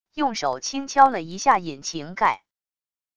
用手轻敲了一下引擎盖wav音频